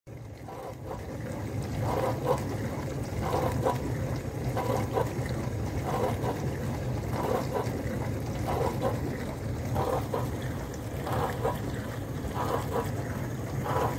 Lave-vaisselle Beko BDIN285D0B bruit du bras
le bruit et plus fort que sur la video
La disposition de la vaisselle joue dans le bruit, mais pour ma part, il ne me paraît pas anormal (bruit de pulvérisation d'eau).
pour moi le bruit est anormal, il y a un sale bruit de mécanique...